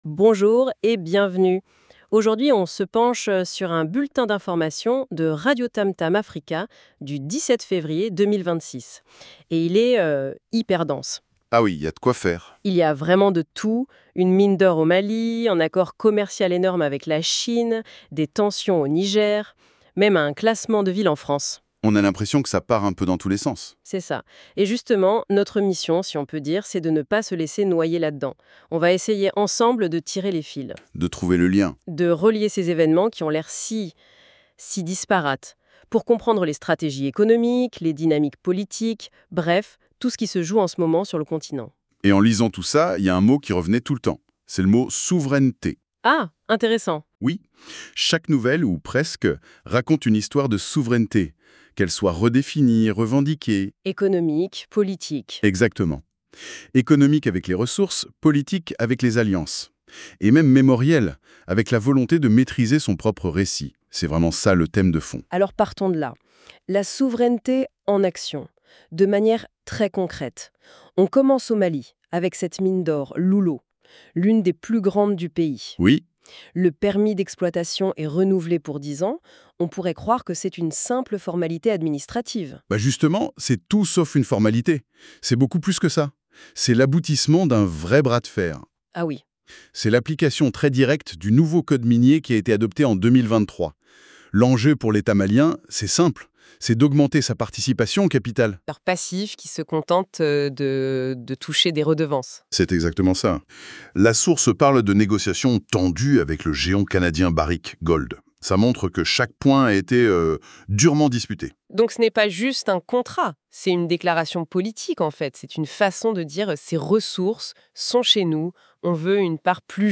RADIOTAMTAM AFRICA – Journal Parlé Présentation